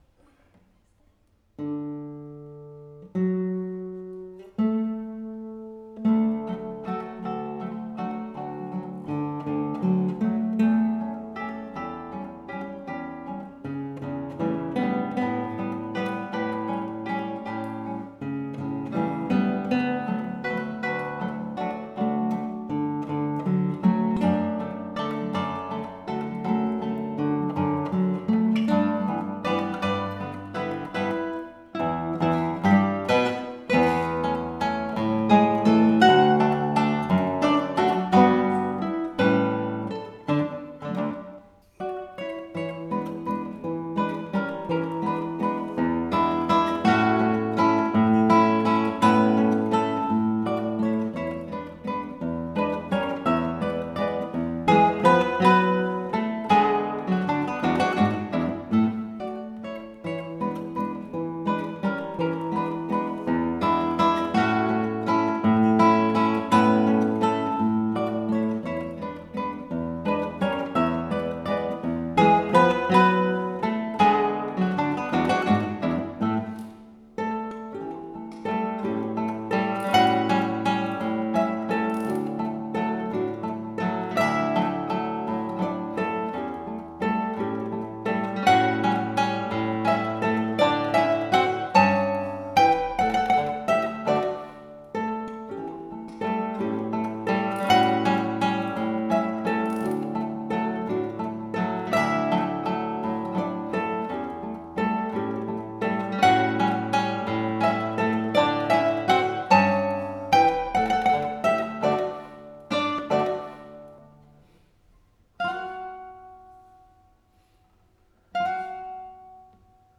美しき青きドナウ模範演奏 | ギターアンサンブル ダ・カーポ